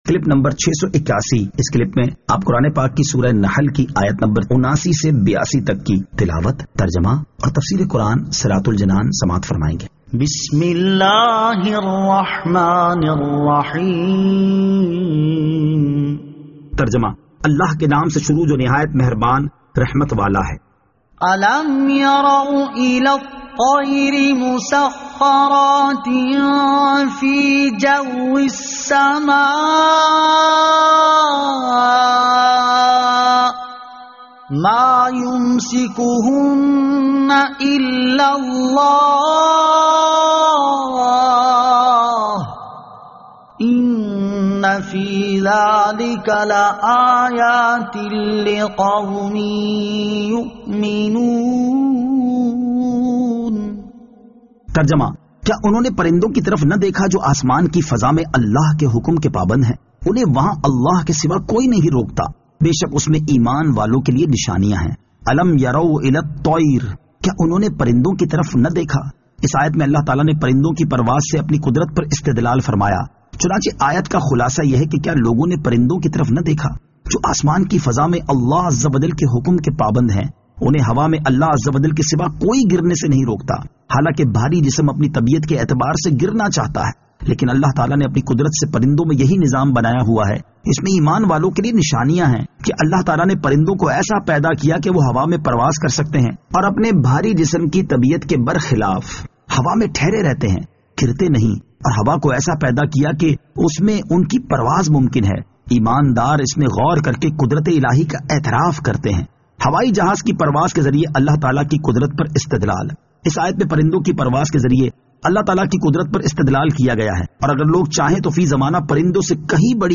Surah An-Nahl Ayat 79 To 82 Tilawat , Tarjama , Tafseer